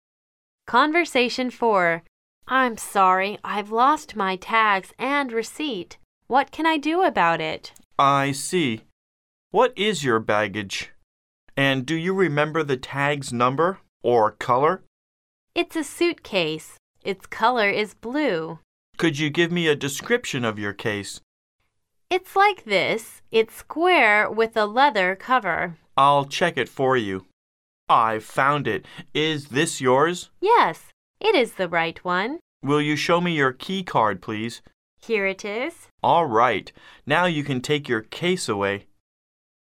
Conversation 4